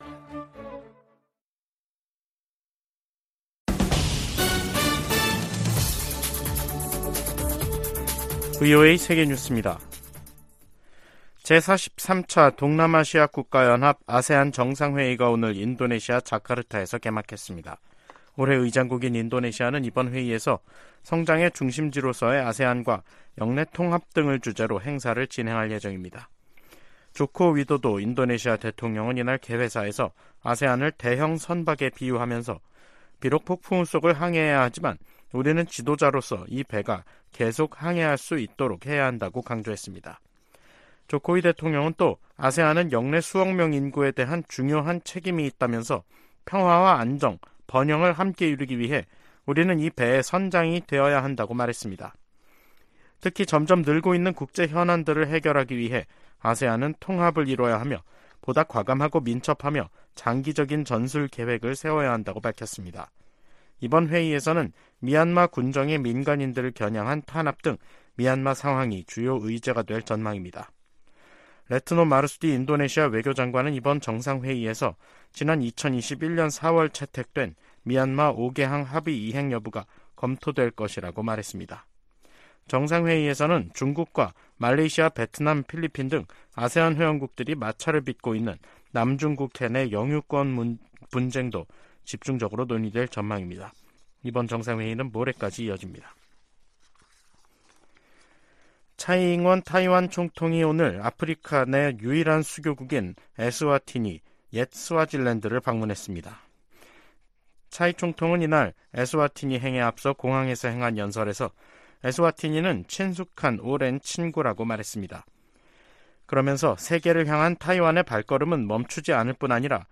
VOA 한국어 간판 뉴스 프로그램 '뉴스 투데이', 2023년 9월 5일 2부 방송입니다. 백악관은 북한 김정은 위원장의 러시아 방문에 관한 정보를 입수했다고 밝혔습니다. 미 국무부는 북한과 러시아의 연합 군사훈련 논의 가능성을 비판했습니다.